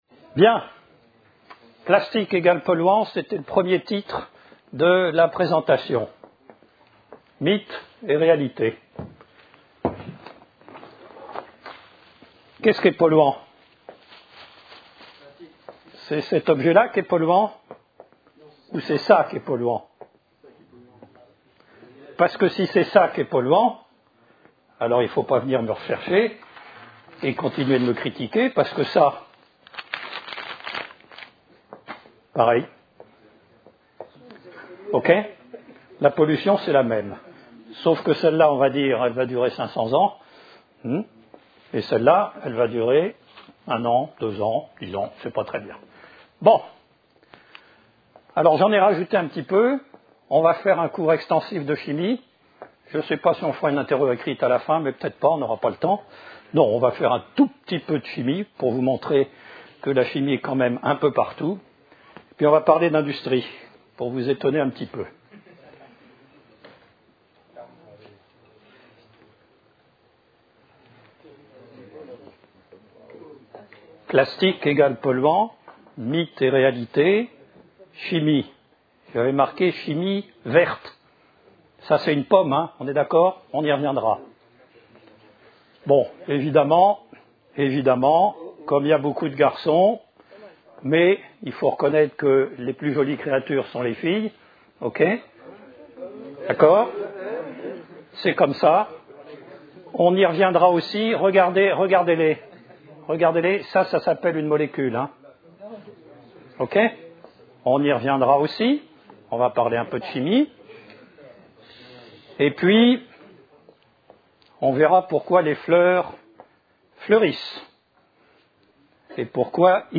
Une conférence de l'UTLS au Lycée La pétrochimie